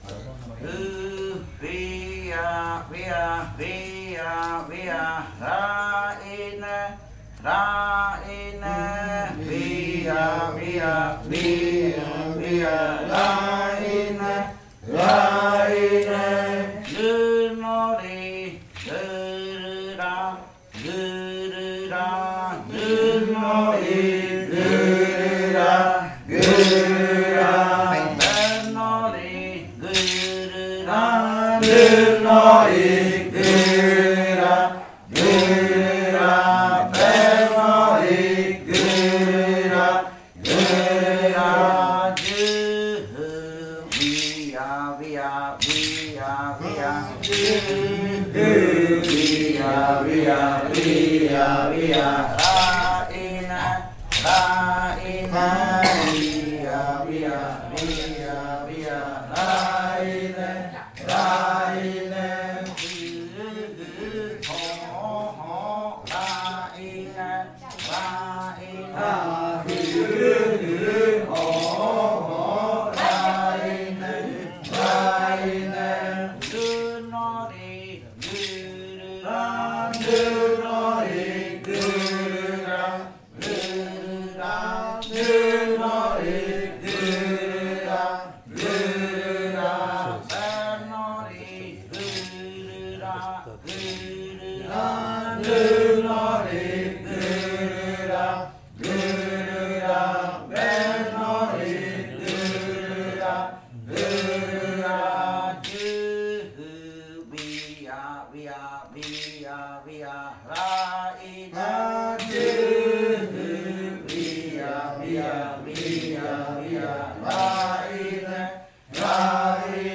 Canto de la variante muruikɨ
Leticia, Amazonas
con el grupo de cantores sentado (en Nokaido). Este canto hace parte de la colección de cantos del ritual yuakɨ murui-muina (ritual de frutas) del pueblo murui, colección que fue hecha por el Grupo de Danza Kaɨ Komuiya Uai con el apoyo de un proyecto de extensión solidaria de la UNAL, sede Amazonia.
with the group of singers seated (in Nokaido).